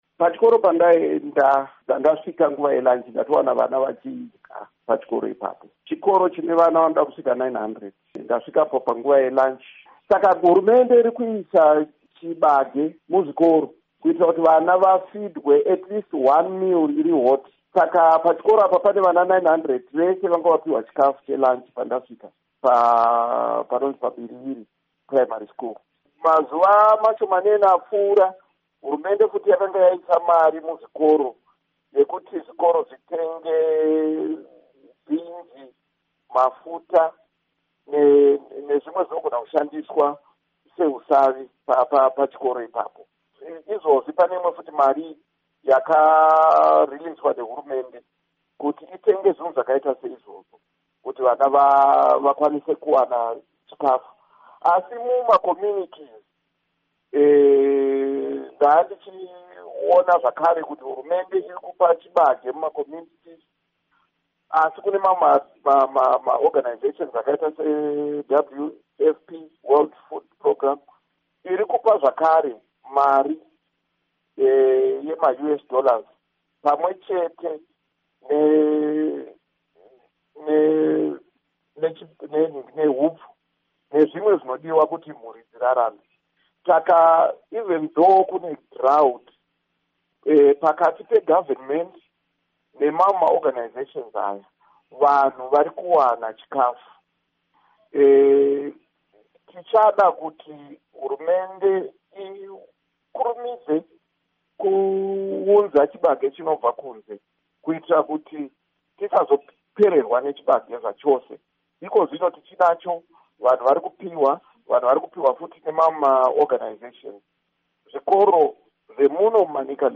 Hurukuro naMuzvinafundo Paul Mavima